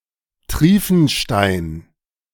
Markt Triefenstein (German: [ˈtʁiːfn̩ˌʃtaɪ̯n] (audio speaker icon